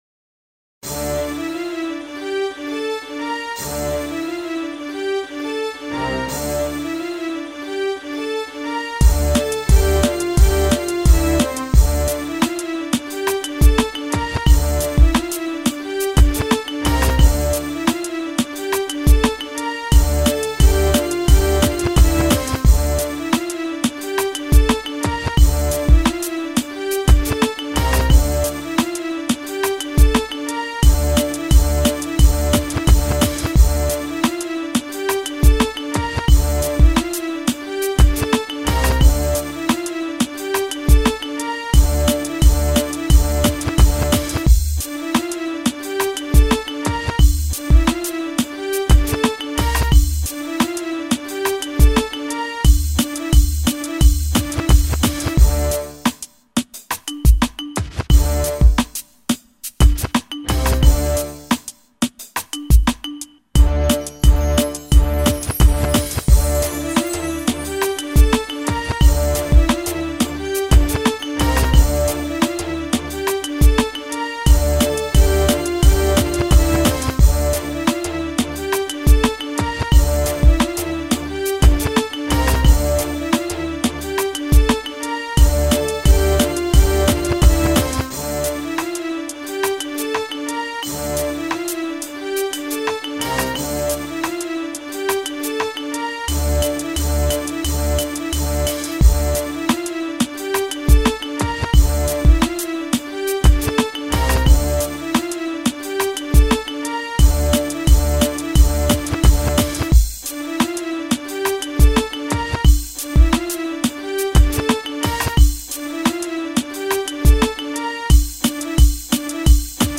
26-rap_instrumental_26.mp3